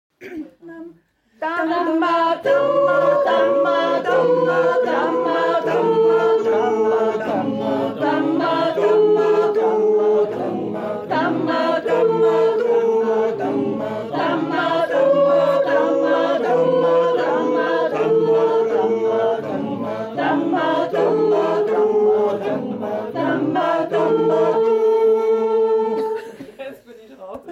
Der Damma du aus der Steiermark beim JodelStammtisch April 2023 gesungen